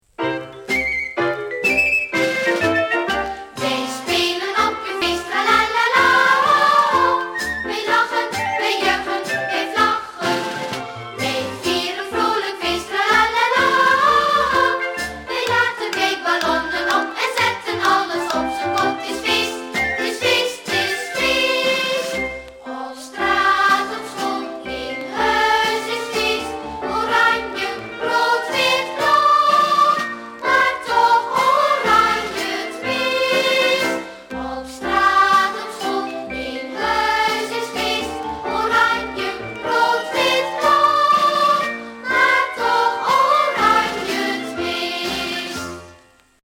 Liedjes